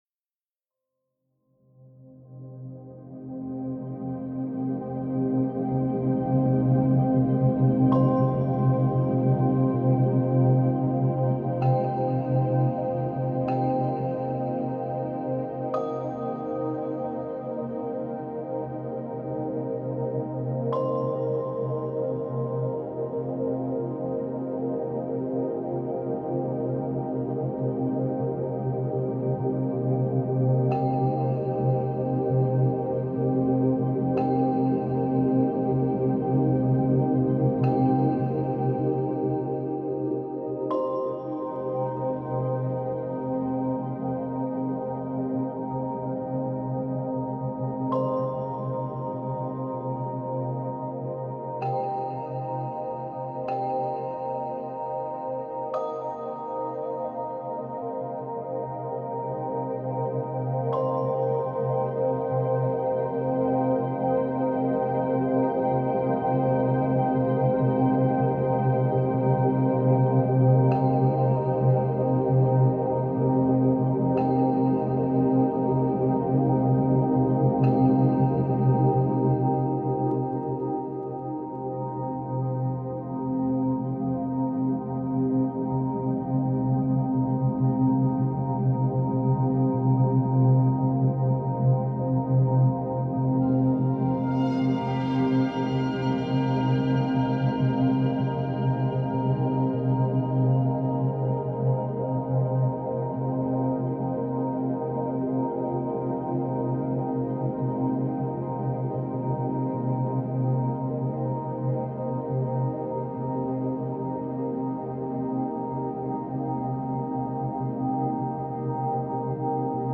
Relaxing Ambient Music